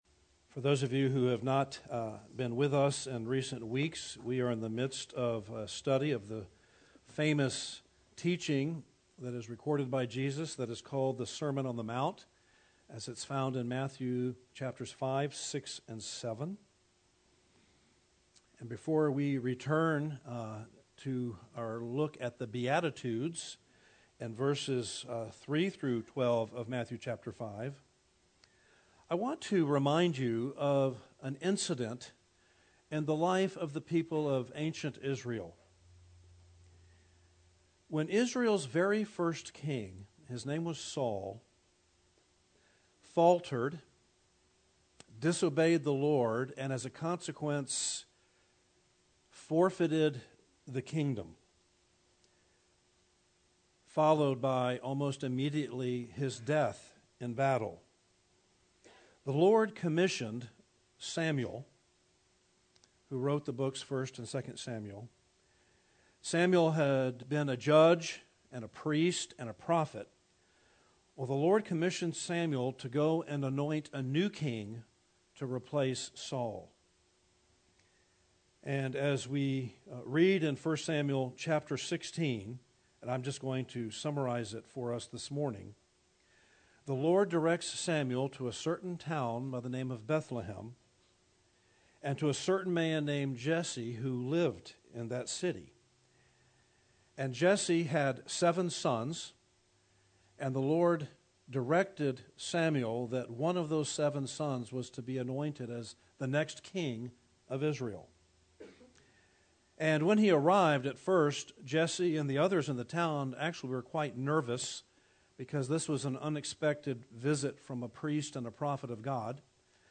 Distinctive Blessings for Kingdom Citizens- Part IV » The Chapel Church of Gainesville, Florida